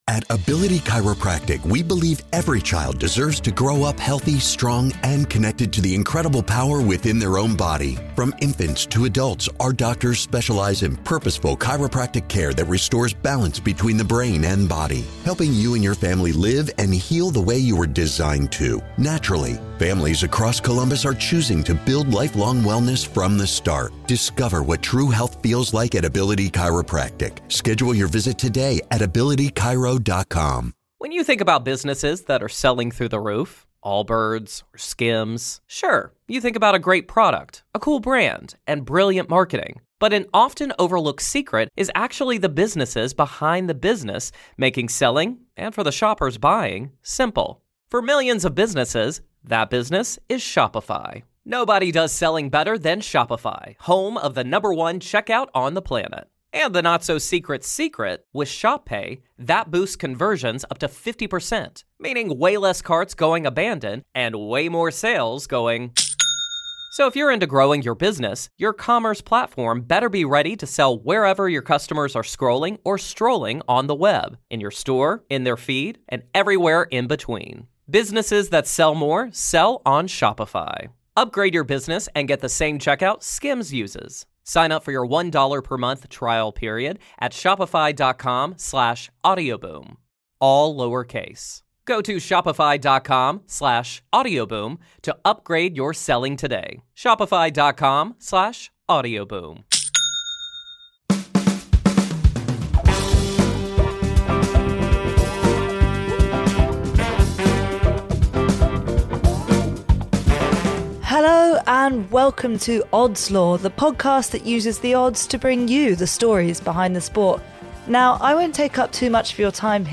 We’re delighted to welcome a very special guest to Odds Law this week, it’s England cricket legend Stuart Broad. We’re talking to him about The Ashes, of course, but also about next summer’s World Cup and England’s hopes of success there….